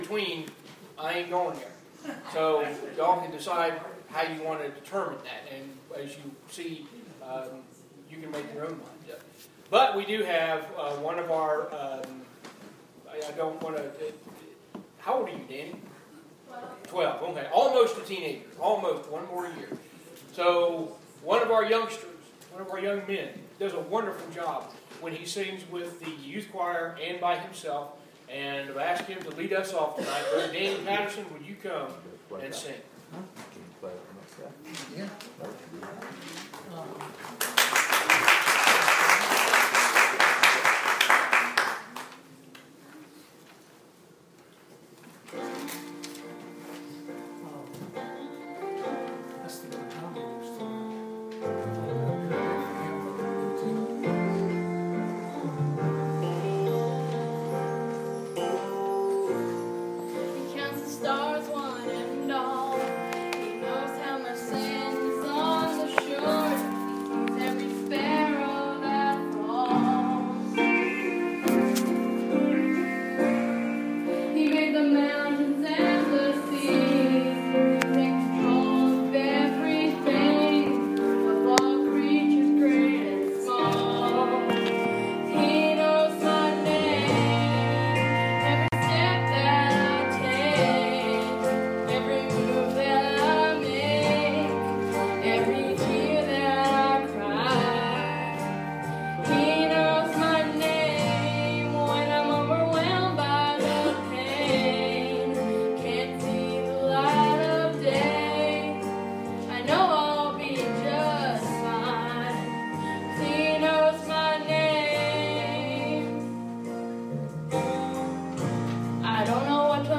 Preached the Evening at Riverview Baptist, 798 Santa Fe Pike, Columbia, TN